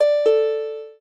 lyre_d1g.ogg